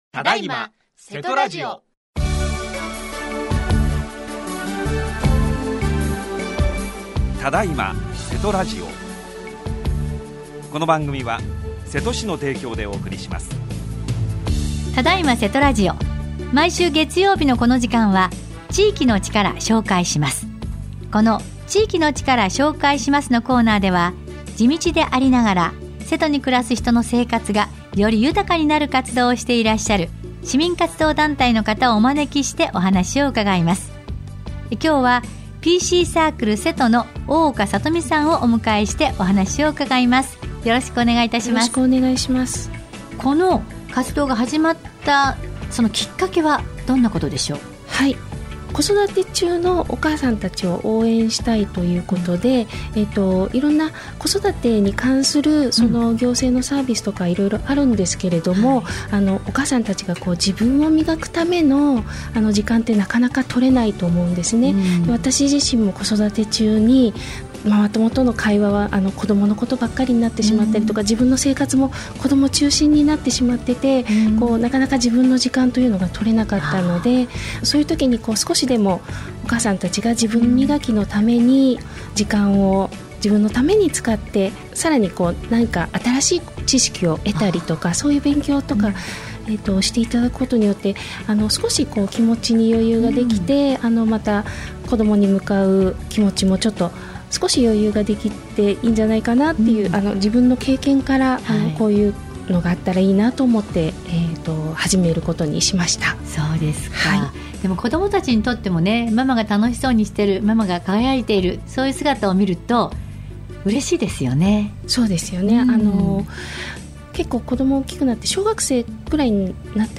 このコーナーでは、地道でありながら、 瀬戸に暮らす人の生活がより豊かになる活動をしていらっしゃる 市民活動団体の方をお招きしてお話を伺います。